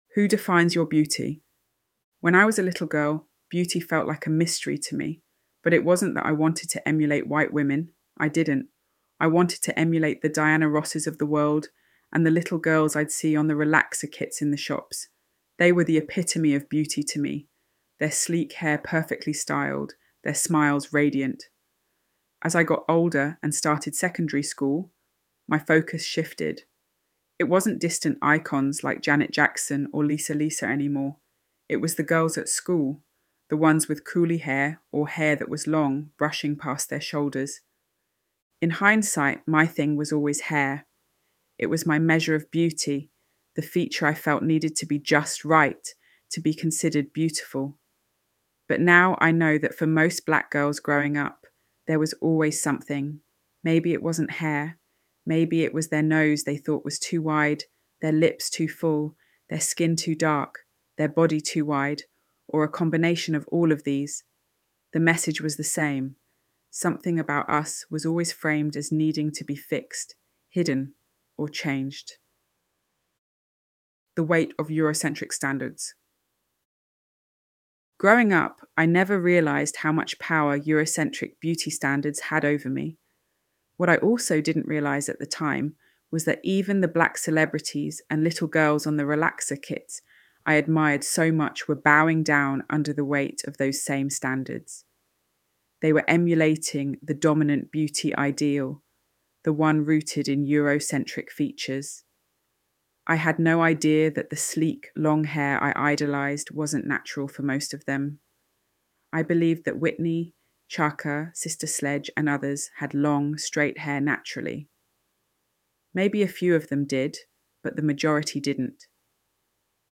ElevenLabs_Who_Defines_Your_Beauty_.mp3